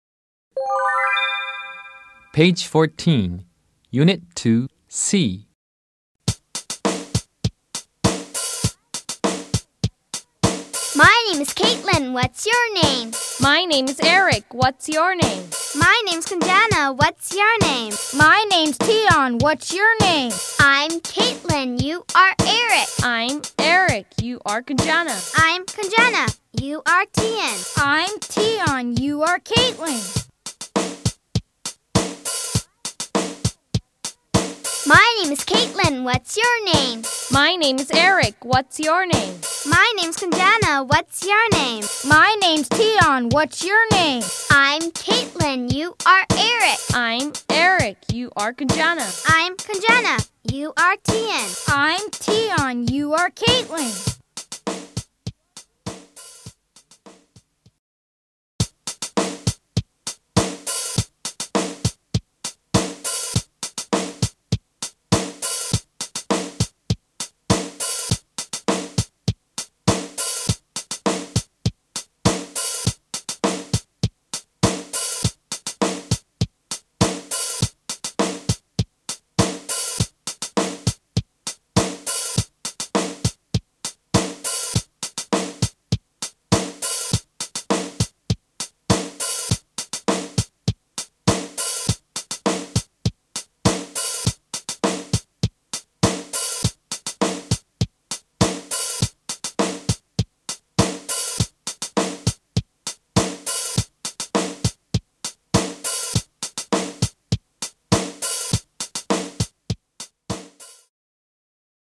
1 Word reading becomes rhythmic reading.